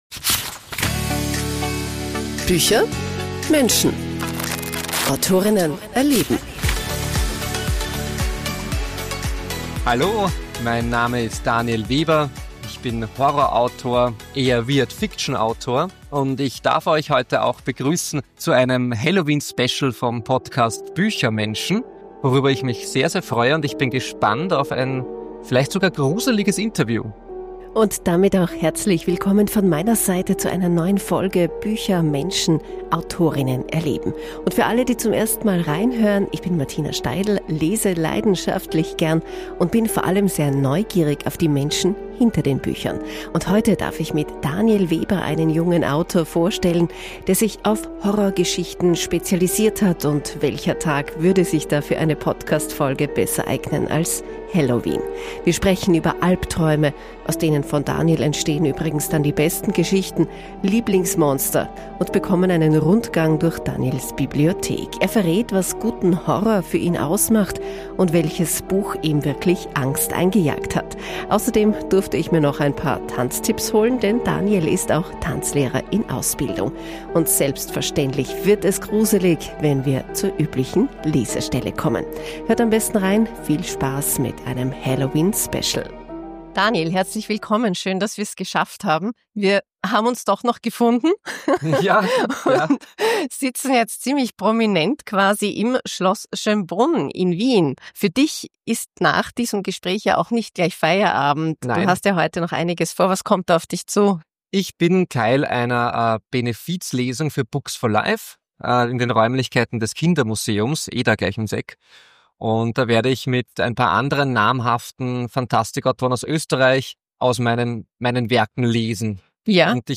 Und selbstverständlich wird es gruselig, wenn wir zu üblichen Lesestelle kommen!